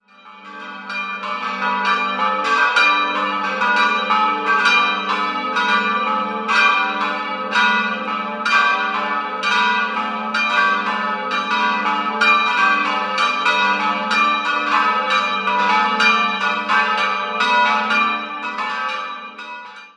4-stimmiges ausgefülltes G-Dur-Geläute: g'-a'-h'-d'' Laut Kirchenführer wurden die Glocken von Georg Hofweber gegossen und im Jahr 1958 geweiht.